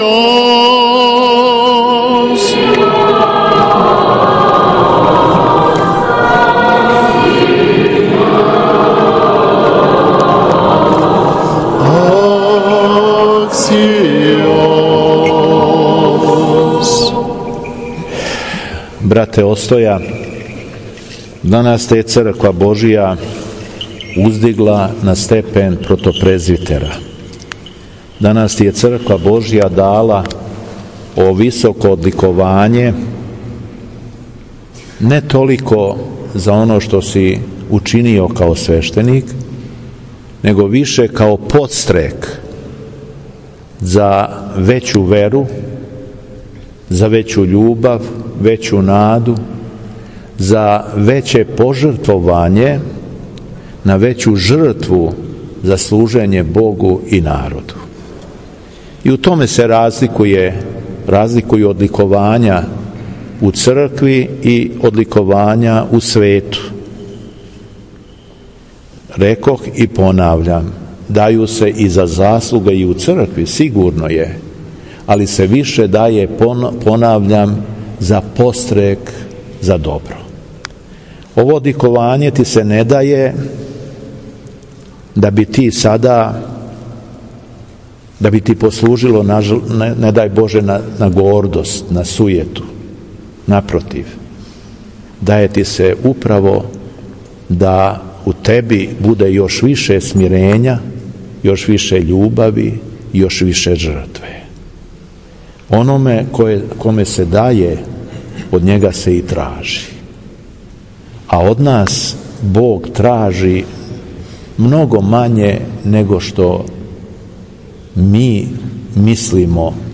У храму Светог великомученика Георгија на Опленцу, дана 9. октобра 2020. године, када прослављамо Светог Јована Богослова, Његово Преосвештенство Епископ шумадијски Господин Јован служио је Свету архијерејску Литургију и парастос поводом 86 година од мученичке смрти Краља Александра I Карађорђевића....
Беседа Његовог Преосвештенства Епископа шумадијског Г. Јована